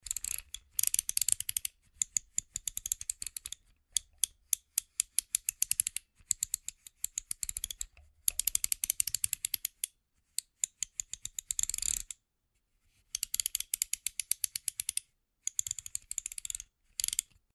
car phone holder turning 1.mp3
Recorded with a Steinberg Sterling Audio ST66 Tube, in a small apartment studio.
car_phonne_holder_turning_1_wdc.ogg